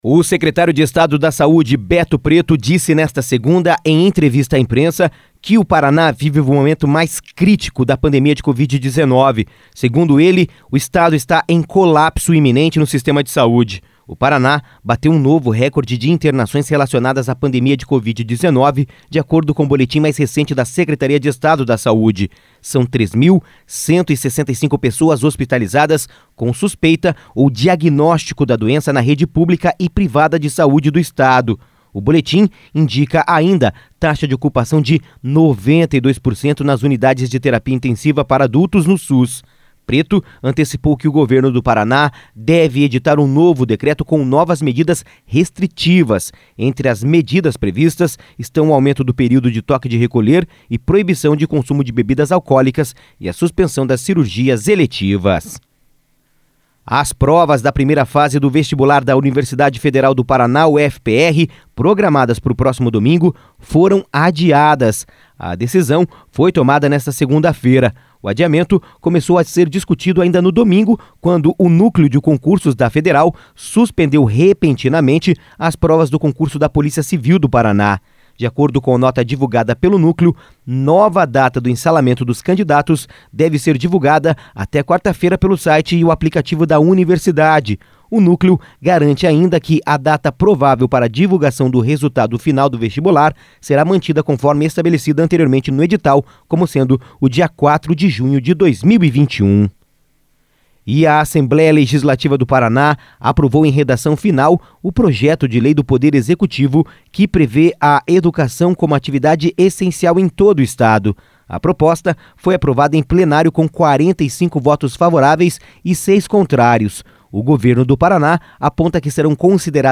Giro de Notícias (SEM TRILHA)